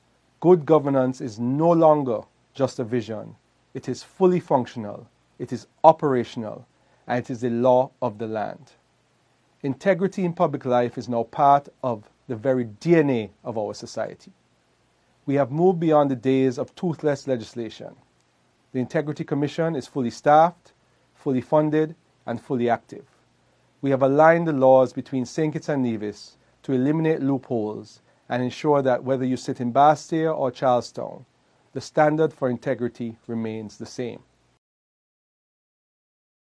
In a national address, Attorney General, the Hon. Garth Wilkin, highlighted the federation’s major progress in transparency and accountability